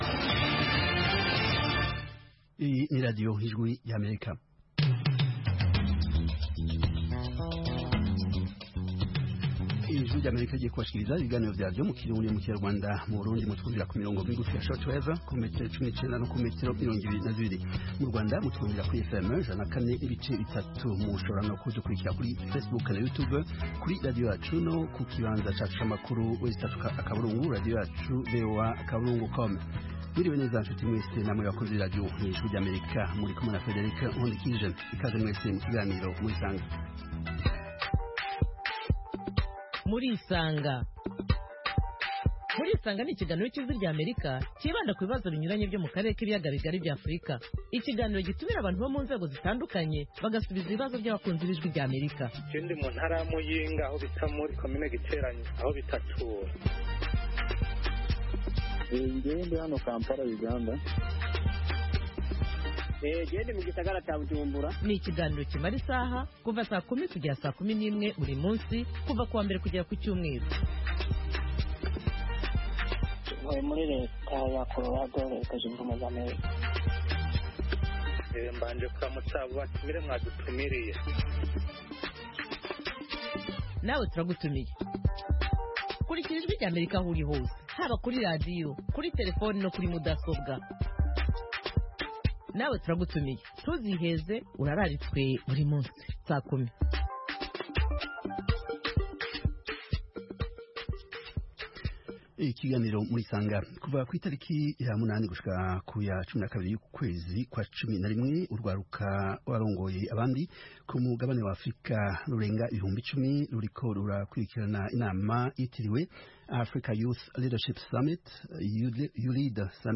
Murisanga (1400-1500 UTC): Murisanga itumira umutumirwa, cyangwa abatumirwa kugirango baganire n'abakunzi ba Radiyo Ijwi ry'Amerika. Aha duha ijambo abantu bifuza kuganira n'abatumirwa bacu, batanga ibisobanuro ku bibazo binyuranye bireba ubuzima bw'abantu.